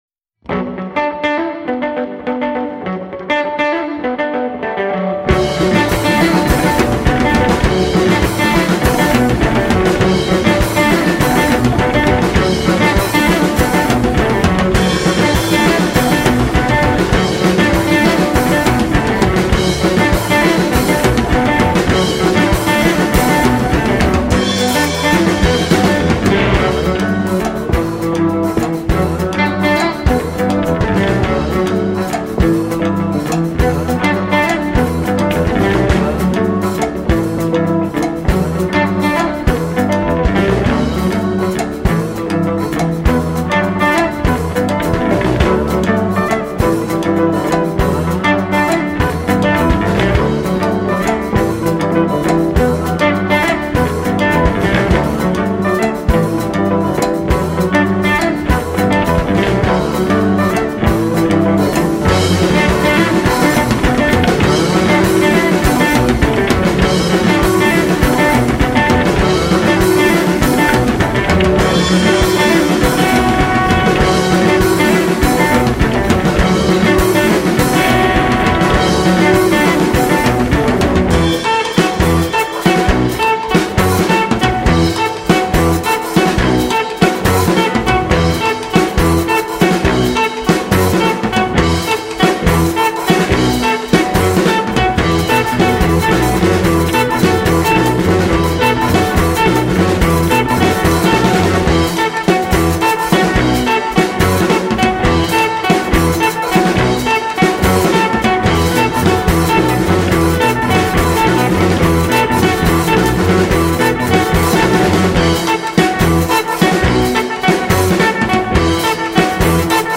instrumental rock trio